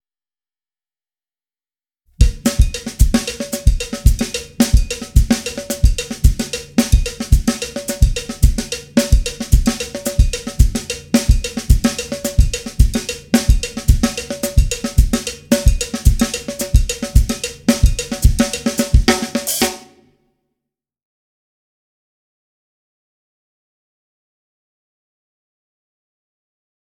Besetzung: Schlagzeug
33 - Songo-Groove 3